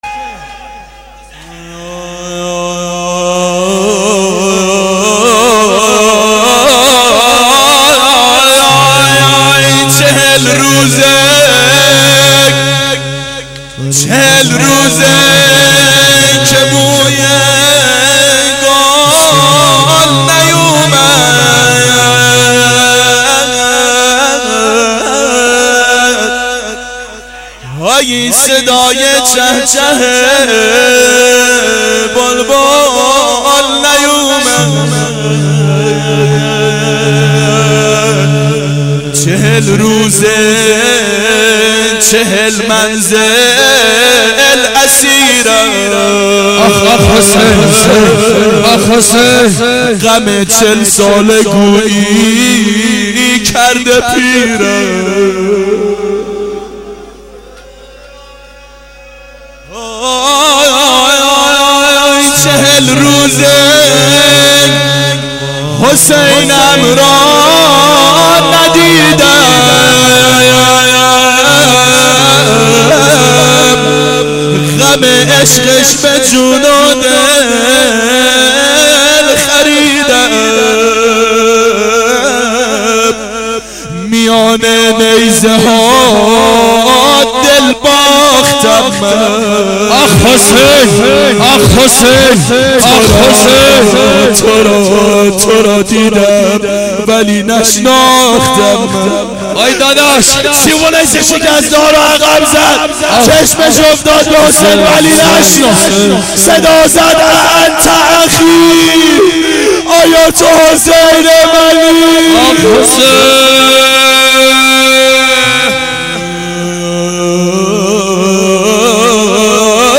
اربعین 90 هیئت متوسلین به امیرالمؤمنین حضرت علی علیه السلام